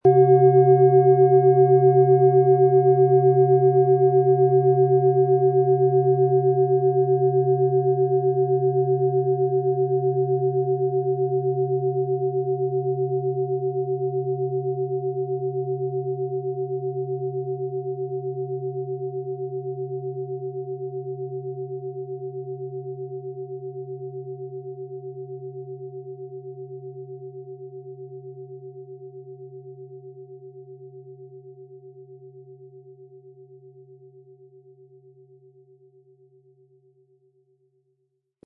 Planetenton
Von erfahrenen Meisterhänden in Handarbeit getriebene Klangschale.
MaterialBronze